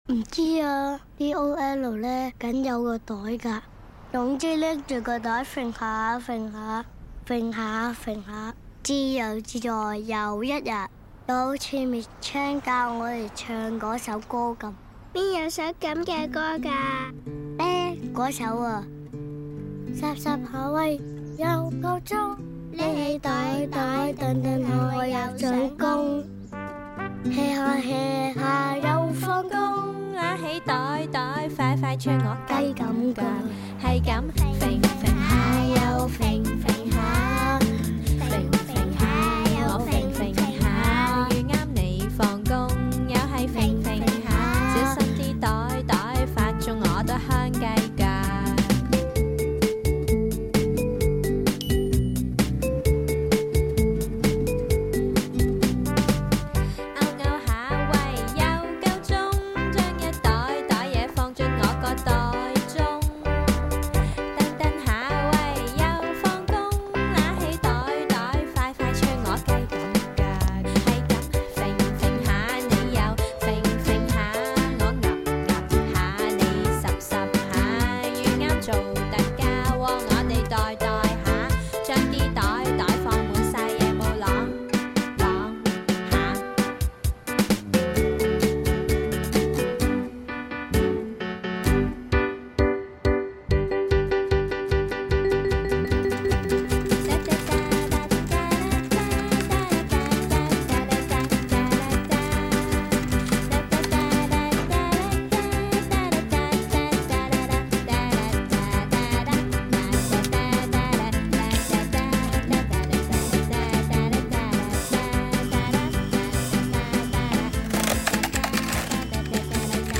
This song is so catchy: